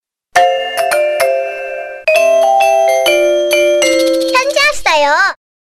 جلوه های صوتی